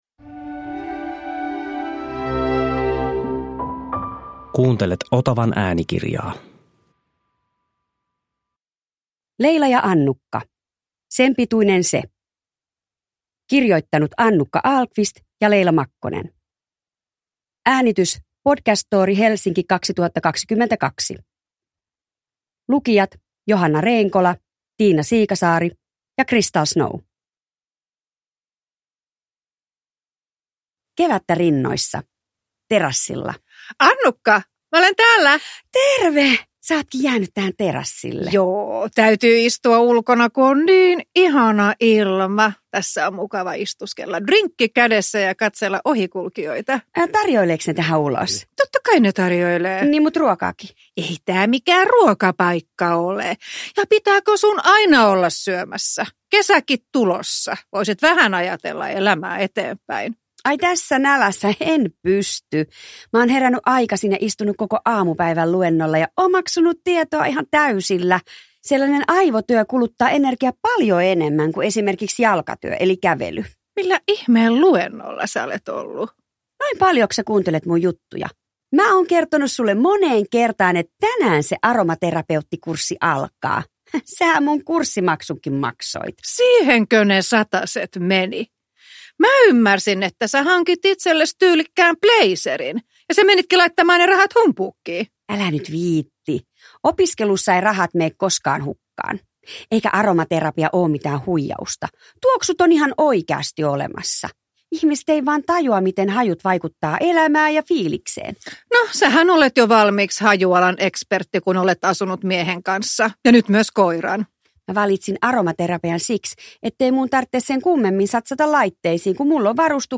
Leila ja Annukka. Sen pituinen se – Ljudbok – Laddas ner